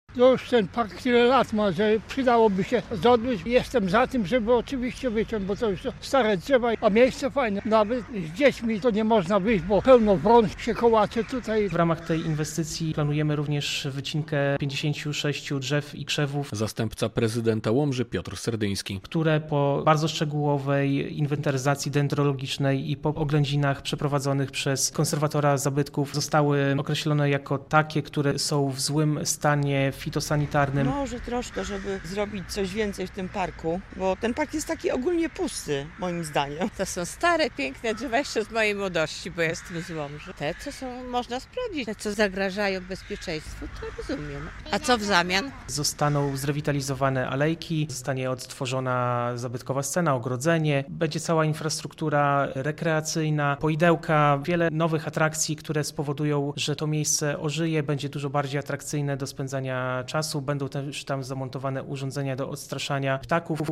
Dlatego trzeba sprawdzić i może wyciąć tylko te, które zagrażają bezpieczeństwu - mówią mieszkańcy Łomży.
Będą też tam zamontowane urządzenia do odstraszania ptaków - mówi zastępca prezydenta Łomży, Piotr Serdyński.